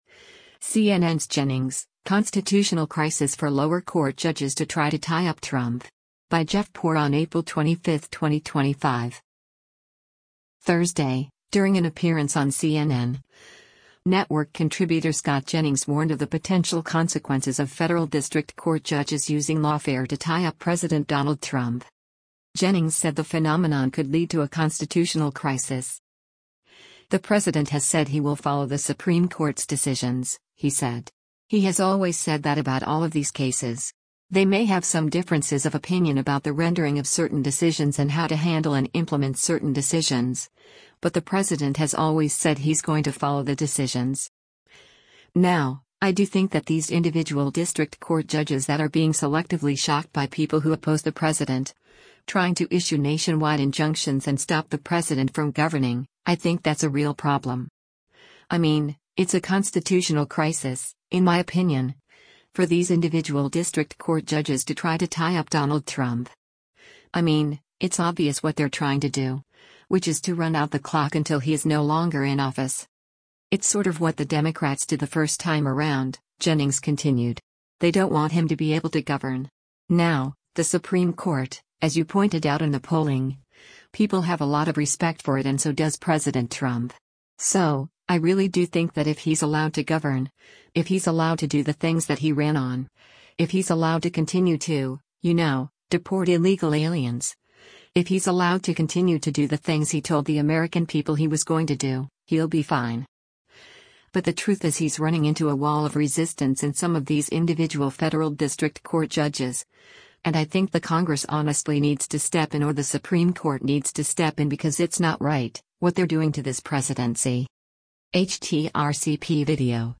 Thursday, during an appearance on CNN, network contributor Scott Jennings warned of the potential consequences of federal district court judges using lawfare to “tie up” President Donald Trump.